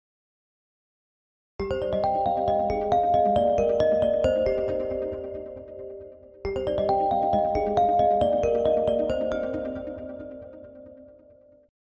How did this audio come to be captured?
Here is that same ringtone in stereo.